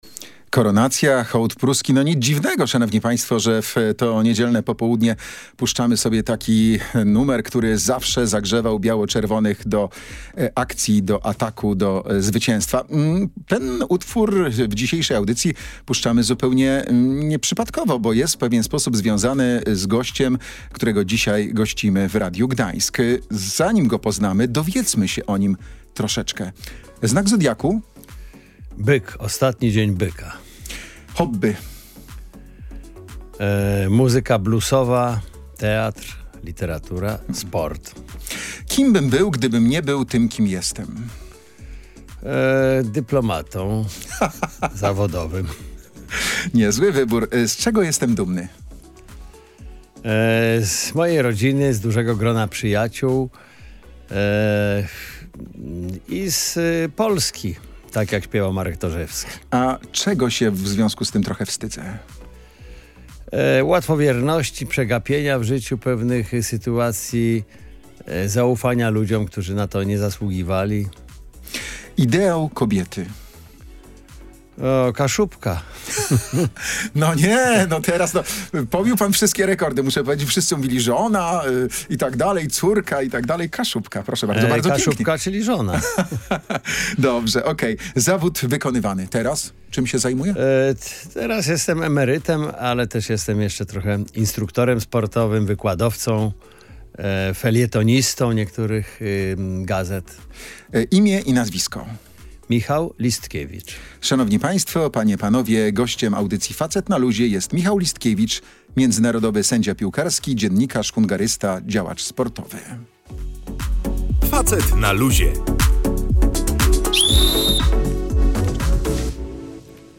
Panowie analizowali wyczyny sędziego w meczu Barcelona – Real oraz interpretowali piłkarskie przepisy.